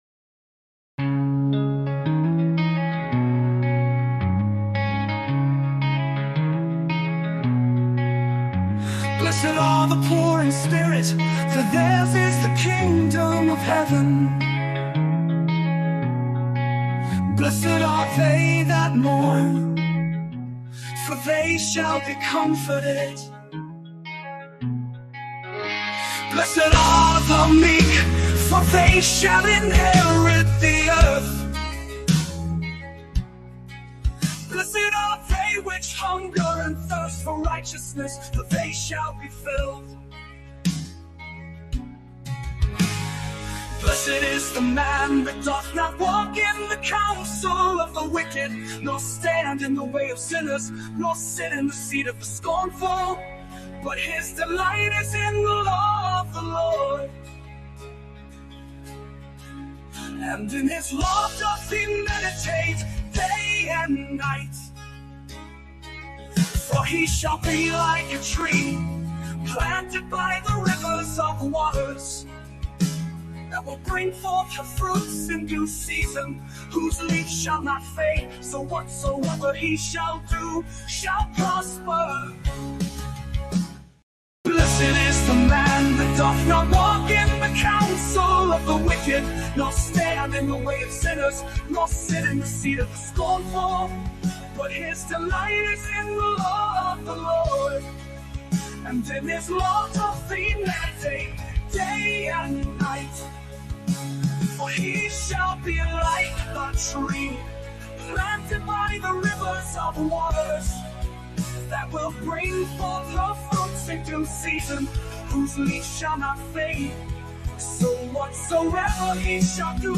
Talk Show Episode, Audio Podcast, Sons of Liberty Radio and The President’s Enumerated Powers & Executive Orders on , show guests , about The President’s Enumerated Powers & Executive Orders, categorized as Education,History,Military,News,Politics & Government,Religion,Christianity,Society and Culture,Theory & Conspiracy